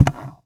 small_box_open_02.wav